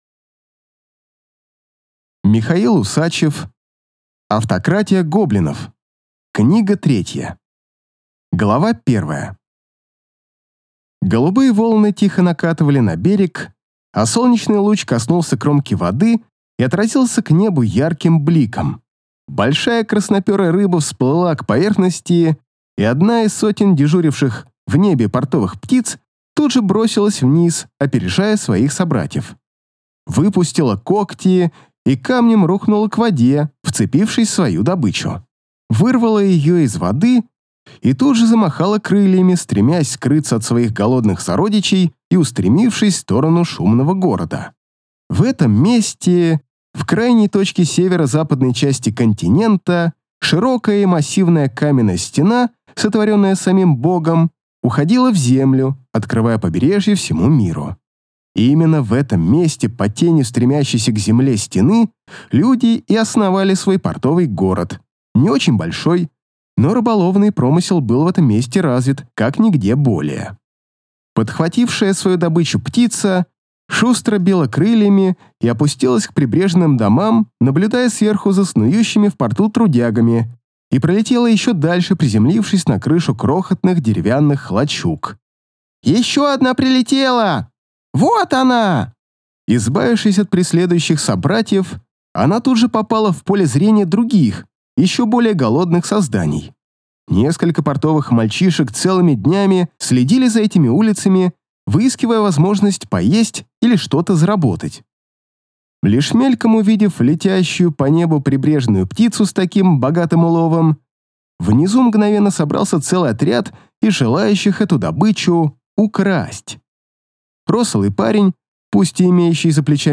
Аудиокнига Автократия гоблинов 3 | Библиотека аудиокниг